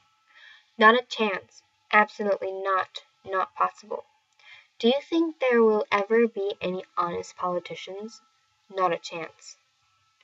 ひとつの可能性すらない、という強い否定を示す俗語です。 英語ネイティブによる発音は下記のリンクをクリックしてください。